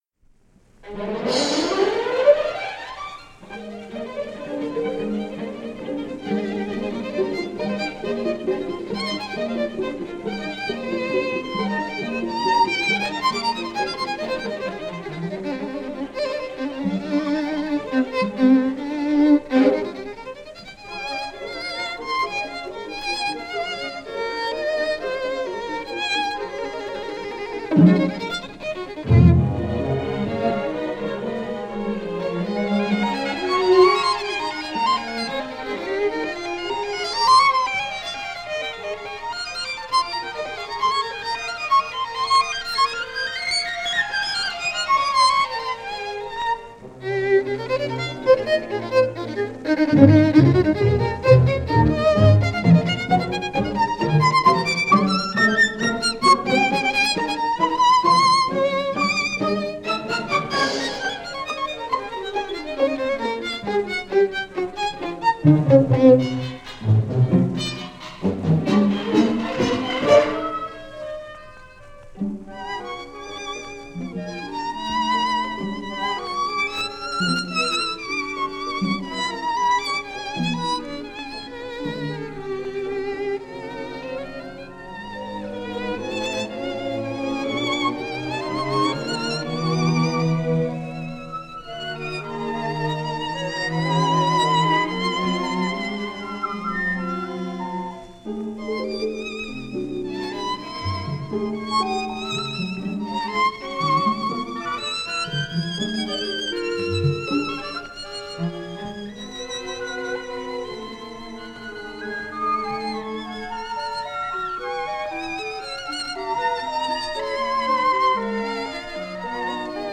Jean Rivier – Violin Concerto – Henri Merckel, violin
ORTF Studio recording, circa 1954
The legendary Belgian violinist Henri Merckel accompanied by Pierre-Michel LeConte and the ORTF Orchestra in this broadcast studio performance of the violin concerto of Jean Rivier.
Rivier-Violin-Concerto.mp3